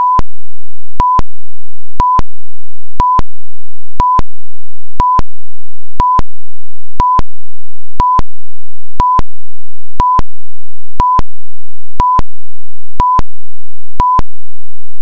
click here to hear an audio transposition of our example waveform. Honestly it does not really sound as birds, I tried to transpose another waveform
chirp_beaugency.ogg